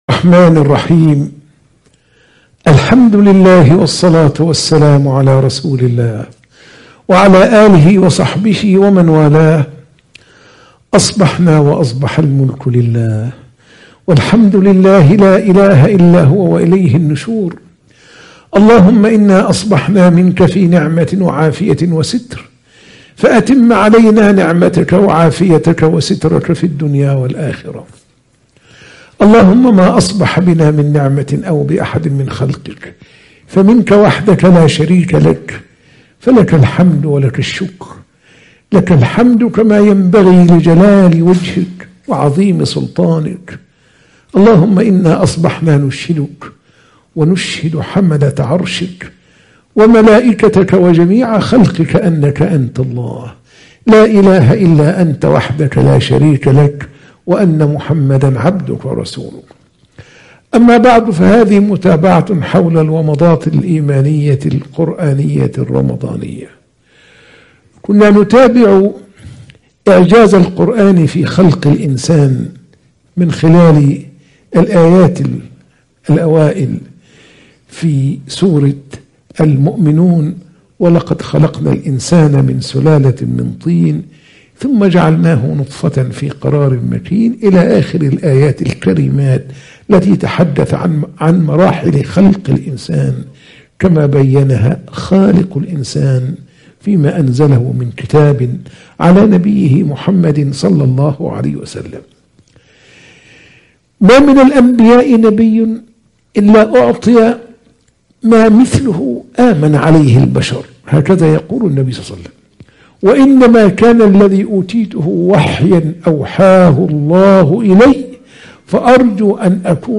درس الفجر